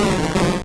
Cri de Psykokwak dans Pokémon Or et Argent.